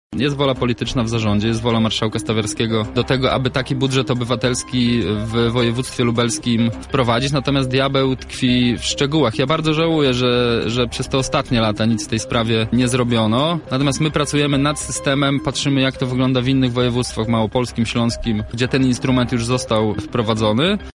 „Samorząd to władza publiczna, władza państwowa, która jest najbliżej ludzi. Rozwiązuje problemy mieszkańców, bardzo skomplikowane. Przede wszystkim tę pracę widać i daje ona wiele satysfakcji” – takimi słowami rozpoczął swoje wystąpienie Wicemarszałek Województwa Lubelskiego Michał Mulawa, który był gościem Porannej Rozmowy Radia Centrum.